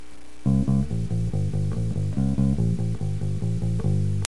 Bass_intro: